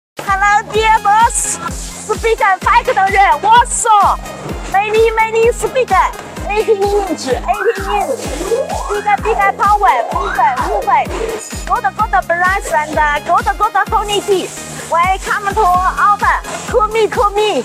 A professional speaker manufacturing factory sound effects free download